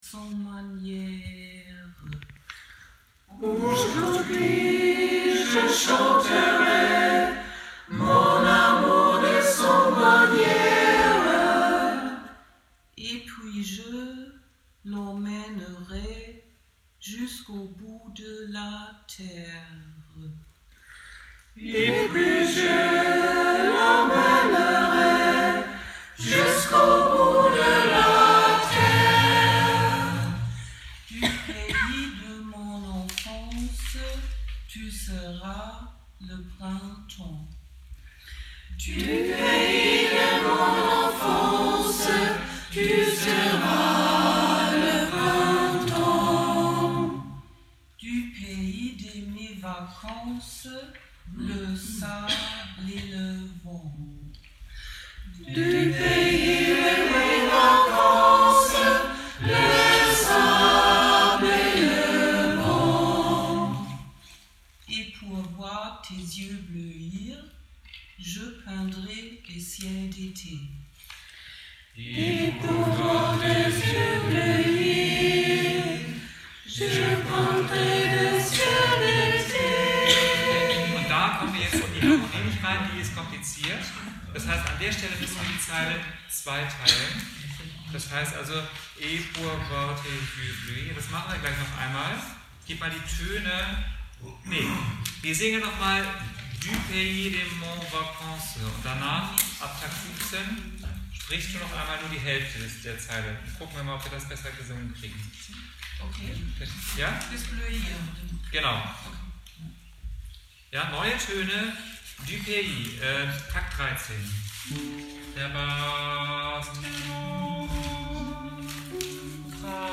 Gesangsübung aus Chorprobe
GesangsuebungAusChorprobe-AjurdhuiJeChantera.mp3